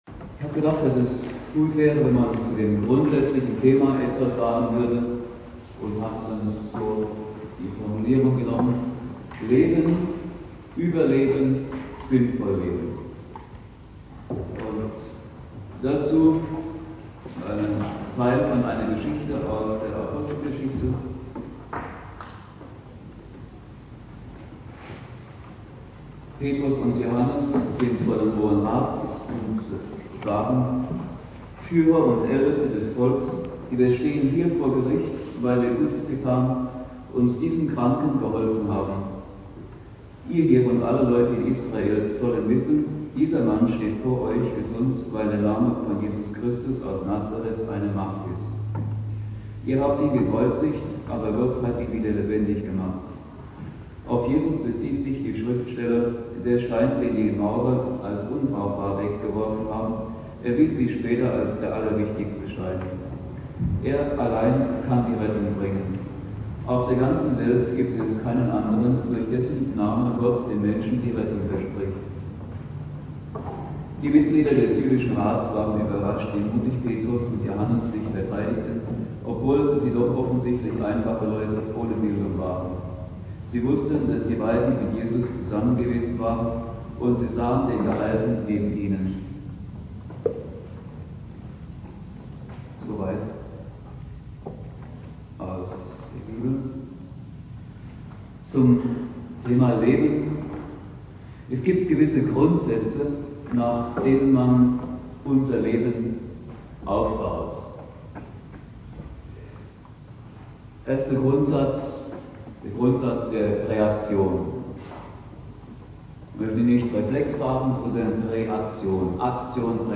Predigt von Christusträger aus Bensheim zu Apostelgeschichte 4,8-14